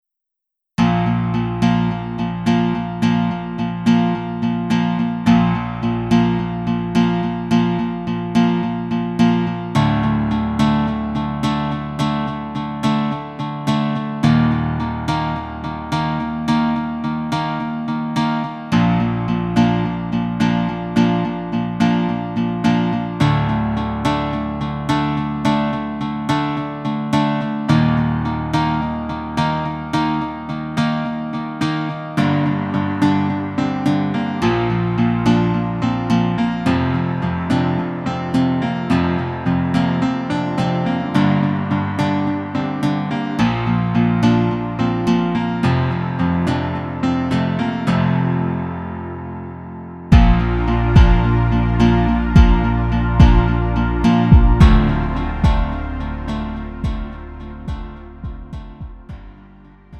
음정 -1키 3:23
장르 구분 Lite MR